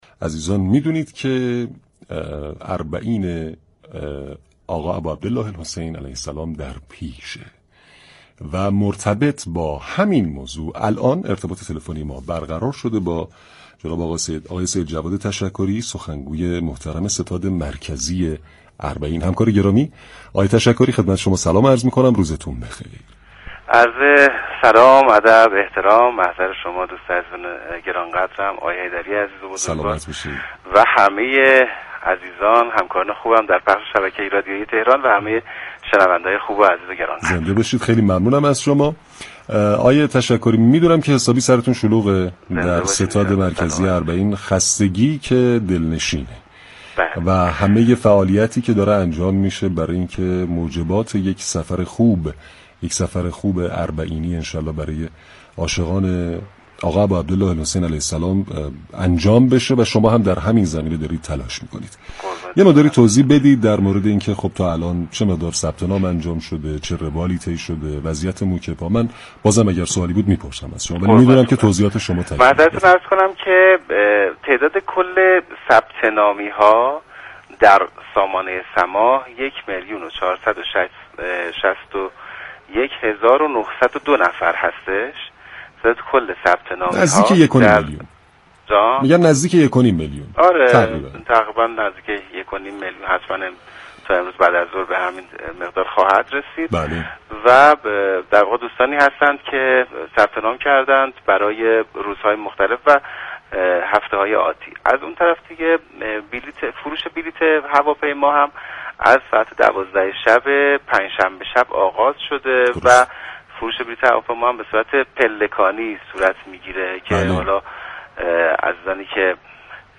در گفت وگو با برنامه سعادت آباد